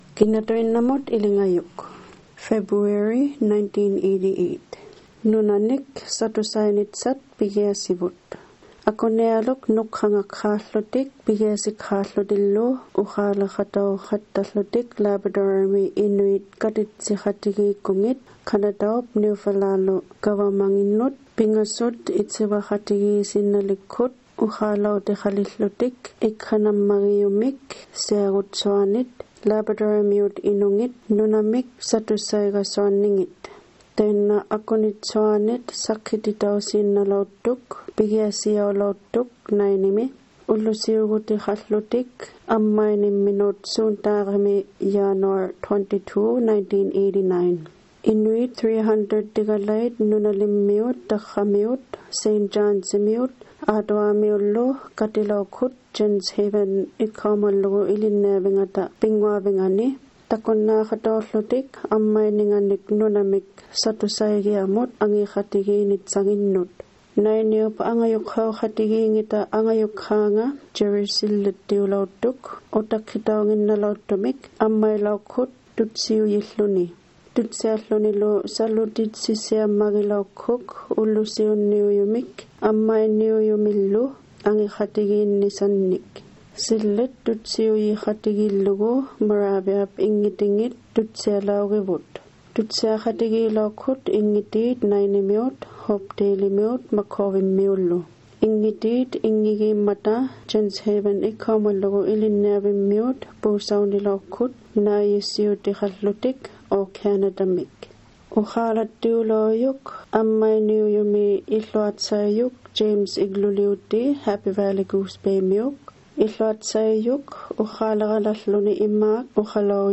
For example, there was a story about the Land Claims Opening that was held in Nain in 1988.
About 300 people from the north coast communities, St. John’s and Ottawa gathered at the Jens Haven Memorial School gym to witness this historical event.